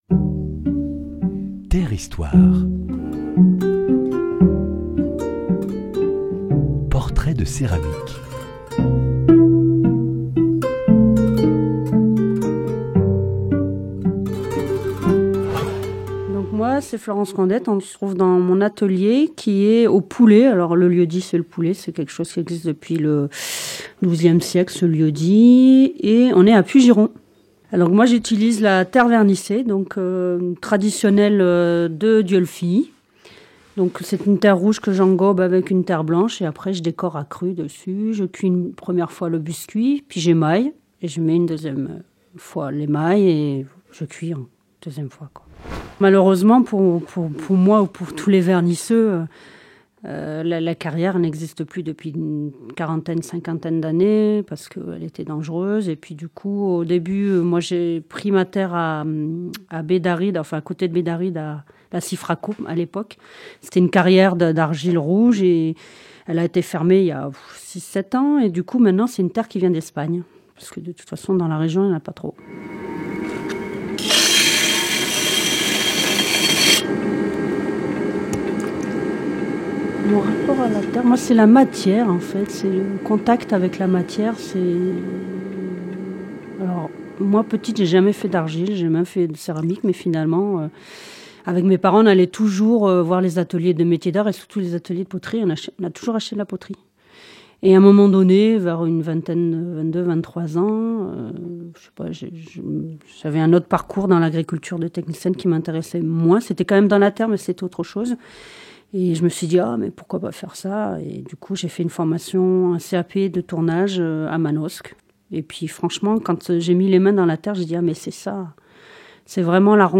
Dans le cadre du 14e marché des potiers de Dieulefit, portraits de céramiques en atelier: matériaux, gestes créatifs, lien avec la matière, 8 potiers évoquent la terre de leur quotidien….